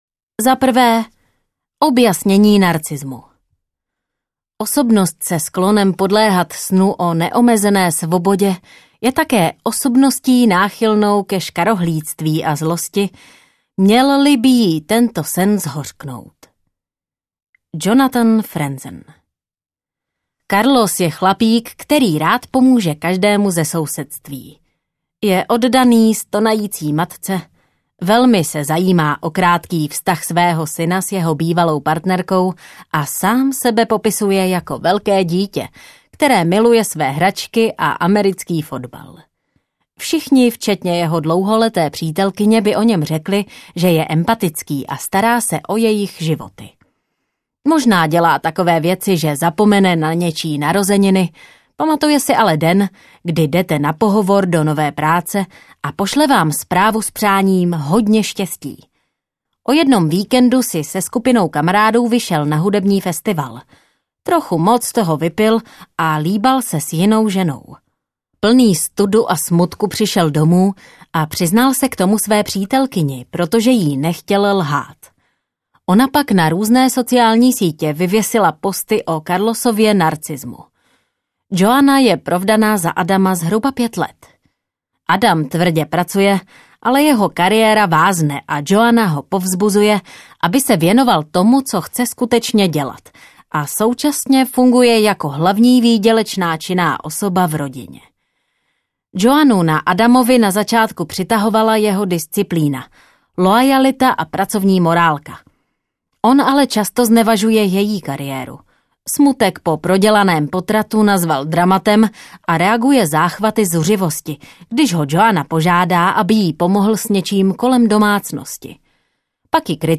Není to tebou audiokniha
Ukázka z knihy